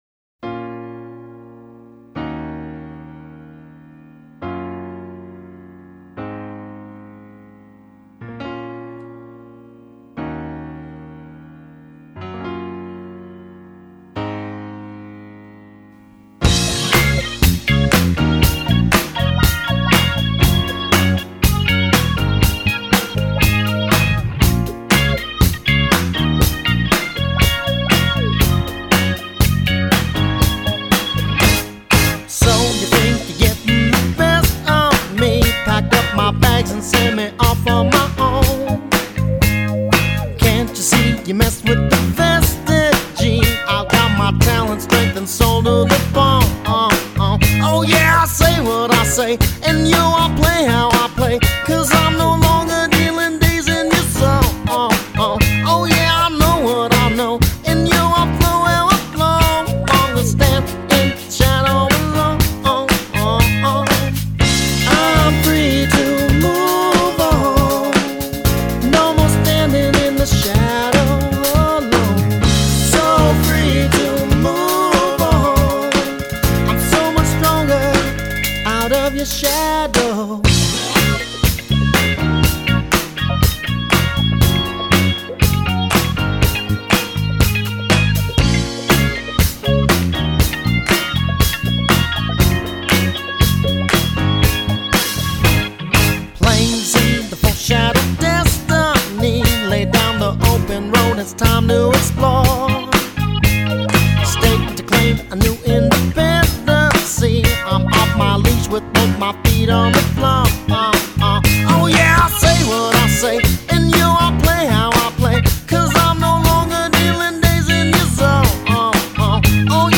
a Boulder Creek-based funk and soul band.